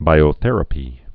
(bīō-thĕrə-pē)